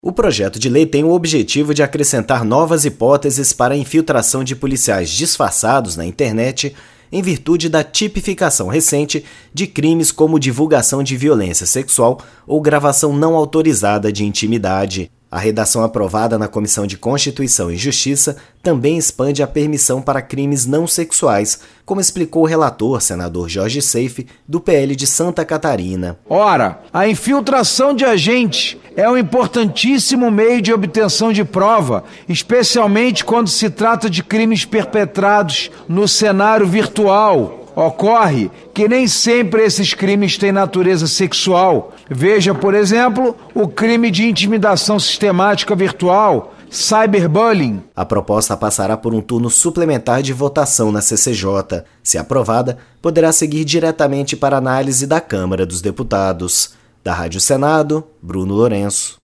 O relator, senador Jorge Seiff (PL-SC), explicou que a proposta admite o disfarce de policiais para investigar crimes como gravação de intimidade sexual não autorizada e cyberbulling.